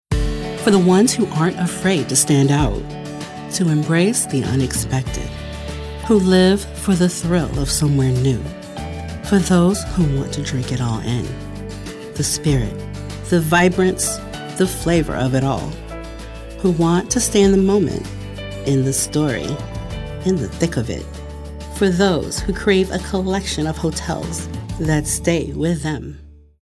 African American, compelling, cool, corporate, folksy, friendly, genuine, girl-next-door, homespun, inspirational, mellow, midlife, motivational, narrative, sincere, smooth, soft-spoken, storyteller, thoughtful, warm